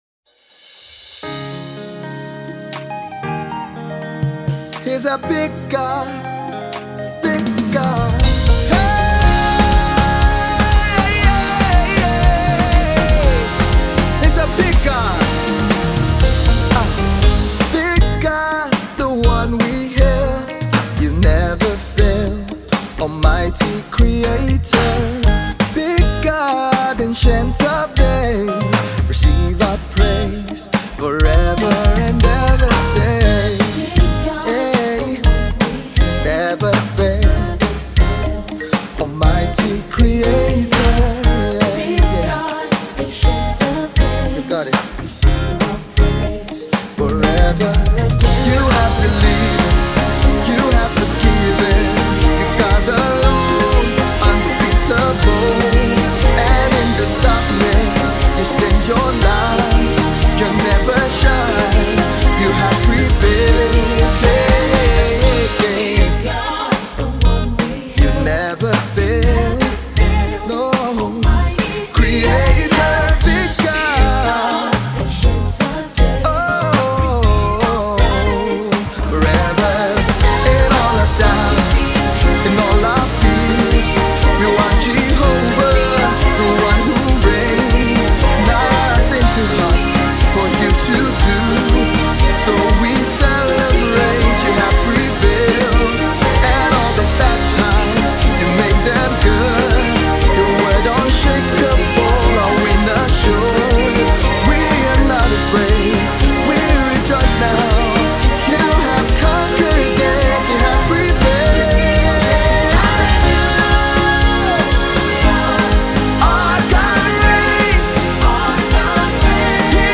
praise anthem
a song that expresses joy, confidence and happiness in God.
It is a song with an Afrocarribean celebratory vibe